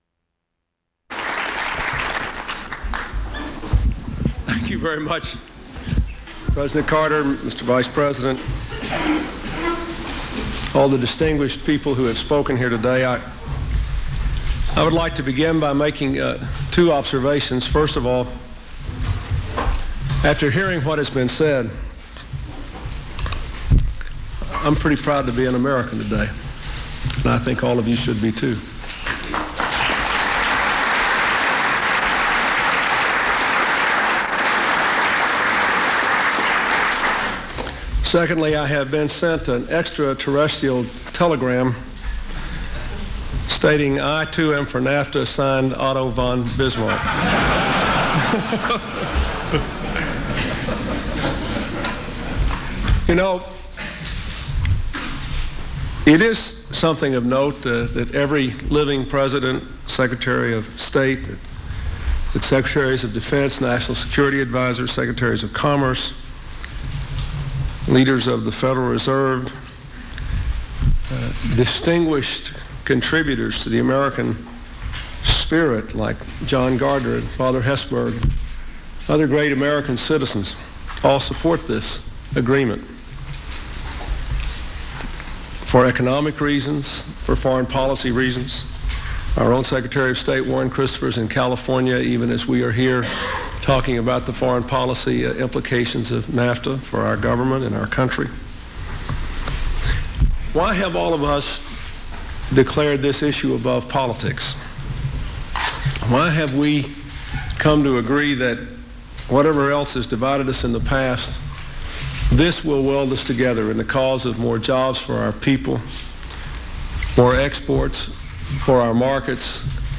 In a speech from the East Room of the White House, President Clinton pushes for passage of the North American Free Trade Agreement (NAFTA). Cites earlier speakers: Economist Paul Samuelson, Secretary of State Henry Kissinger, President Jimmy Carter.
Broadcast on C-SPAN, Nov. 2, 1993.